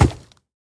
Minecraft Version Minecraft Version snapshot Latest Release | Latest Snapshot snapshot / assets / minecraft / sounds / mob / piglin_brute / step2.ogg Compare With Compare With Latest Release | Latest Snapshot
step2.ogg